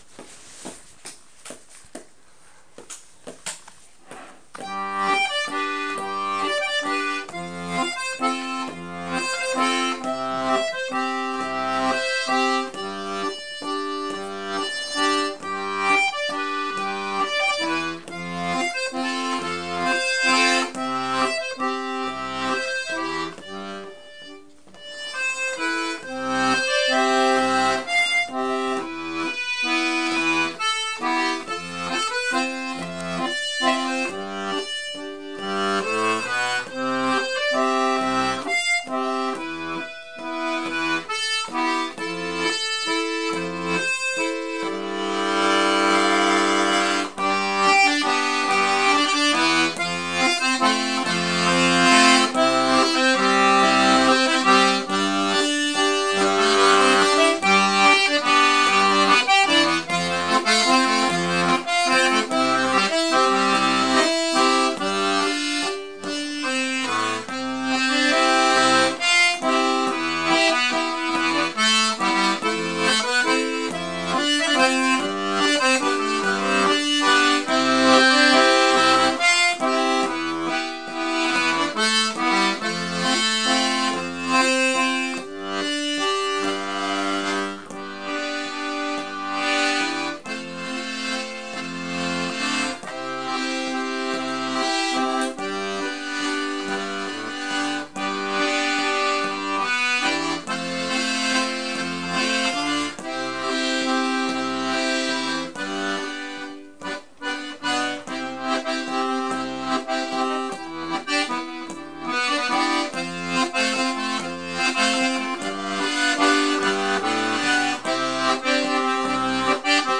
lo talhier del diableton l'atelier d'accordéon diatonique